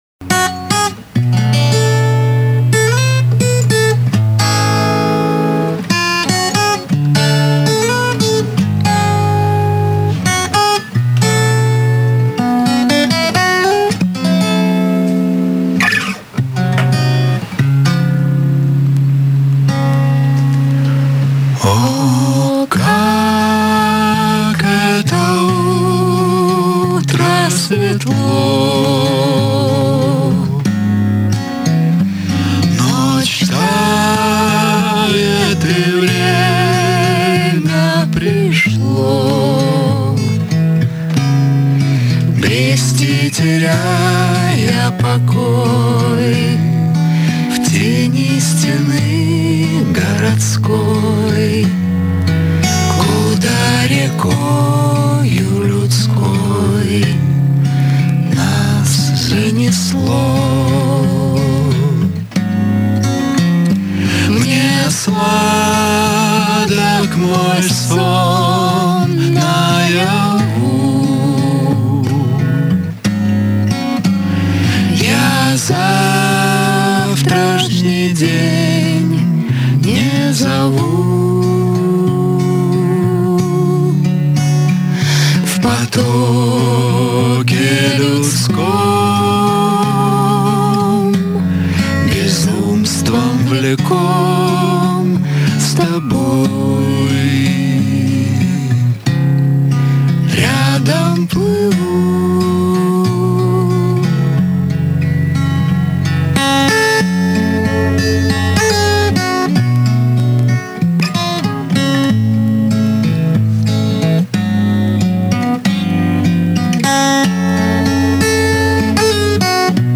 Известный российский дуэт